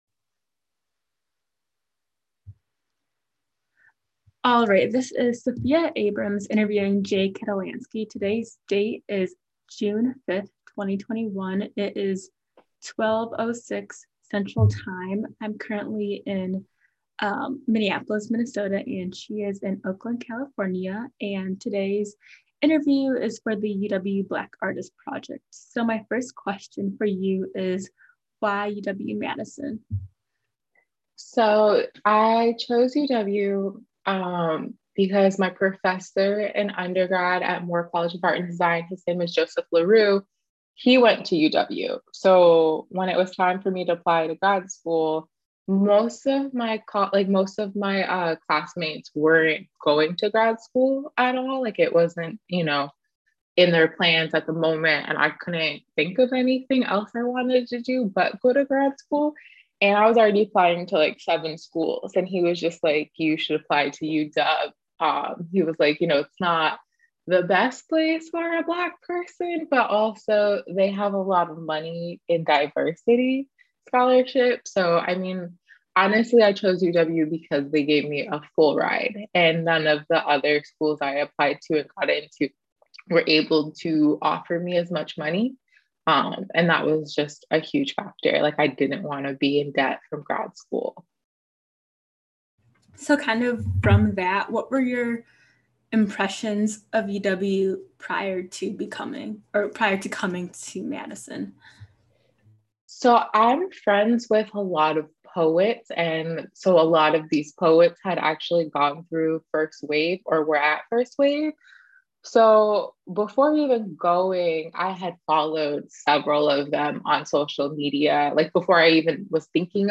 University of Wisconsin-Madison Oral History Program